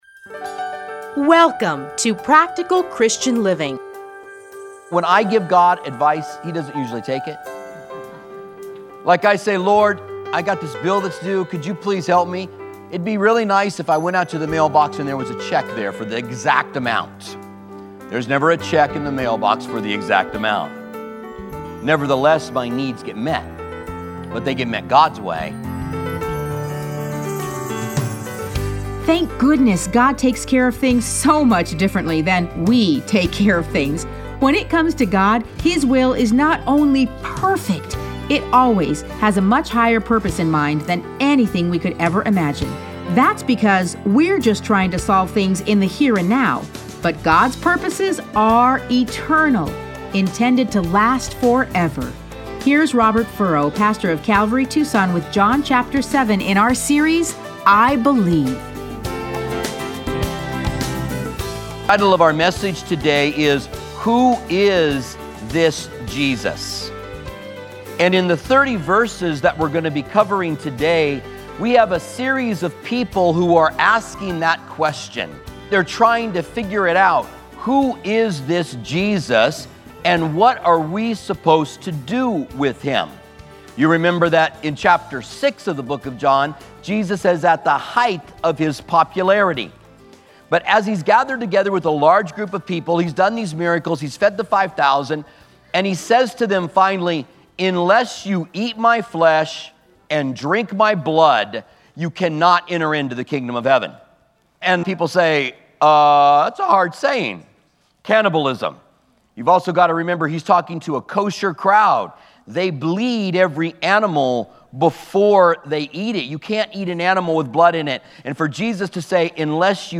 Listen to a teaching from John 7:1-31.